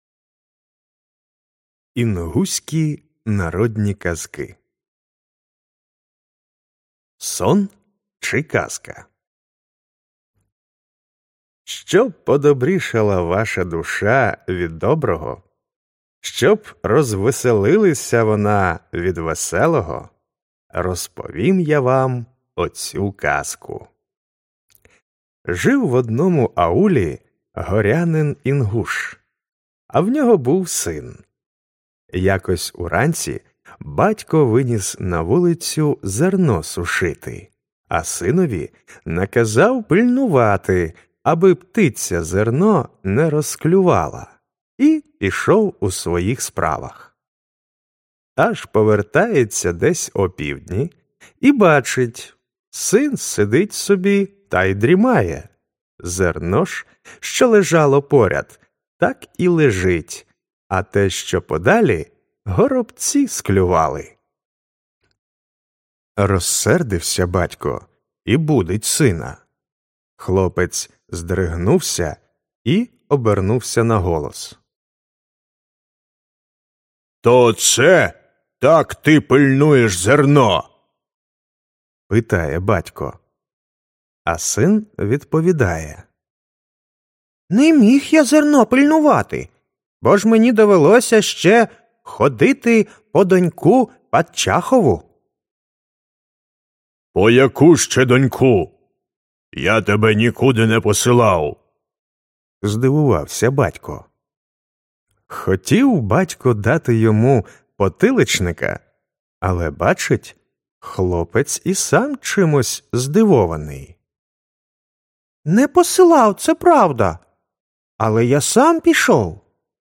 Аудіоказка Сон чи казка